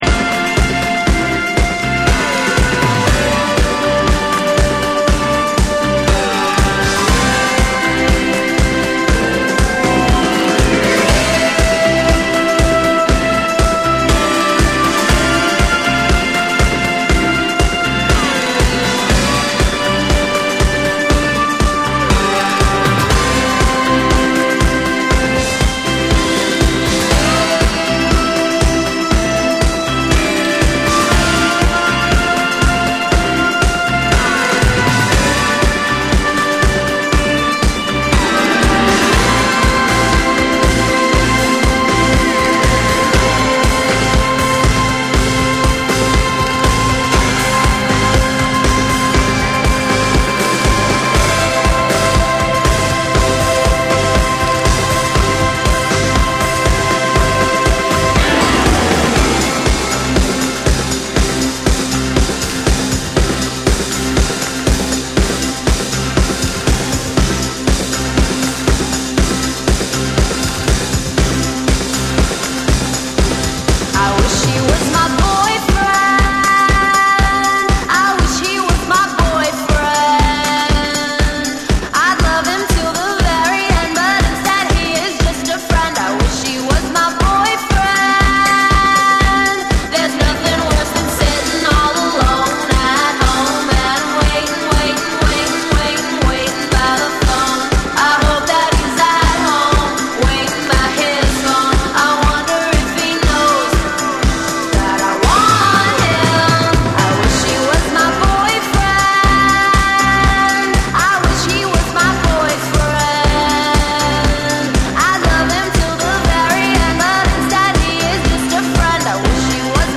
オリジナルの甘く爽やかなメロディを生かしつつ、ダンスフロアでも映える1枚！
TECHNO & HOUSE / NEW WAVE & ROCK